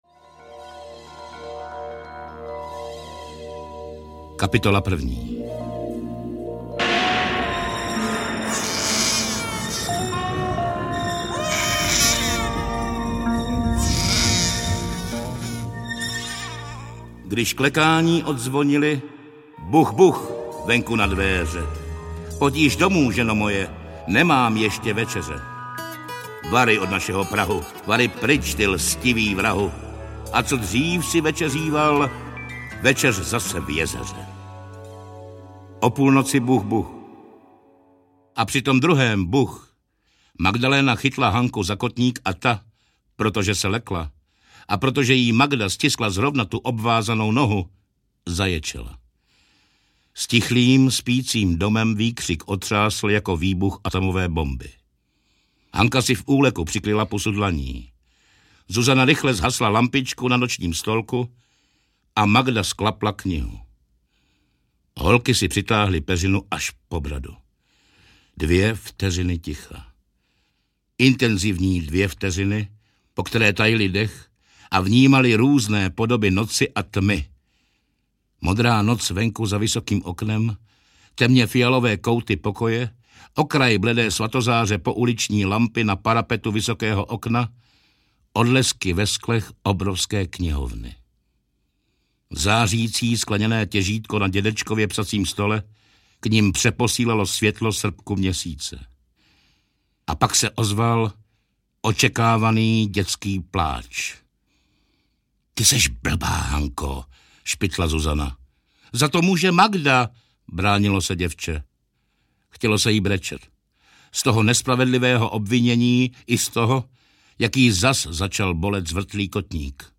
Audio knihaJeště není konec
Ukázka z knihy
• InterpretNorbert Lichý